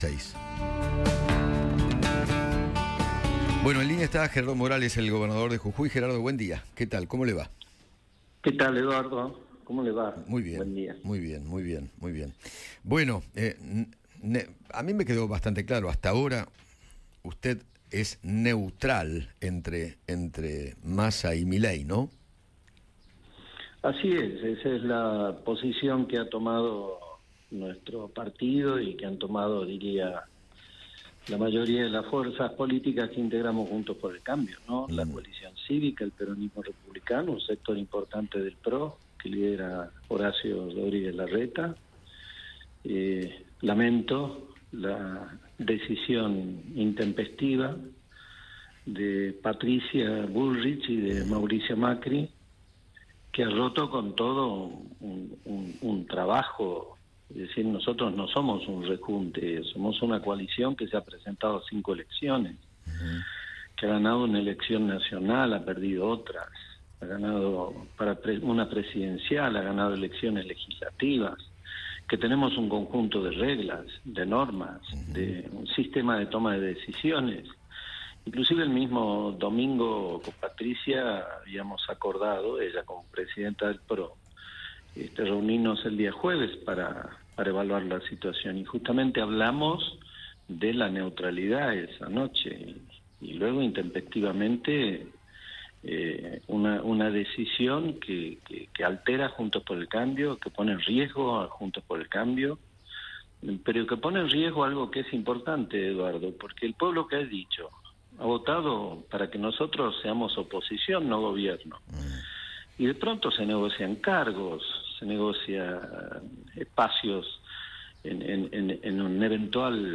Gerardo Morales, gobernador de Jujuy, dialogó con Eduardo Feinmann sobre la decisión de Patricia Bullrich y Mauricio Macri de apoyar a Javier Milei en el balotaje.